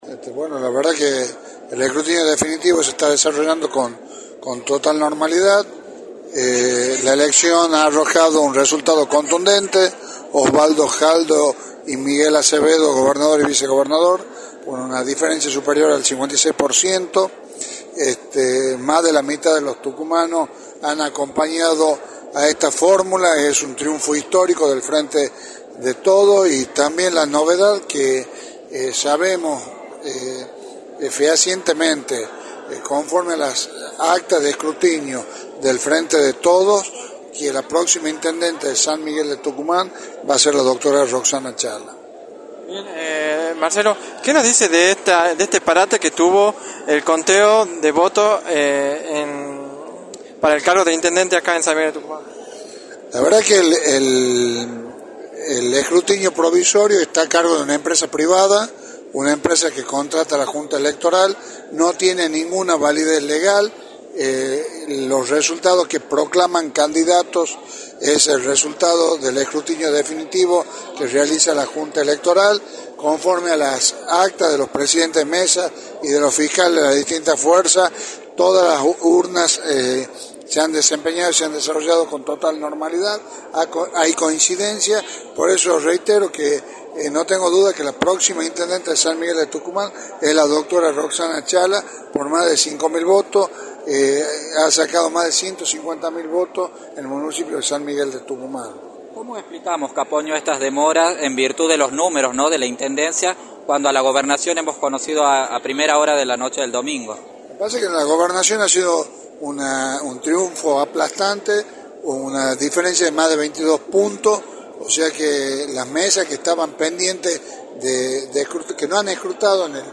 Marcelo Caponio aseguró en Radio del Plata Tucumán, por la 93.9, que el escrutinio definitivo se está desarrollando con total normalidad.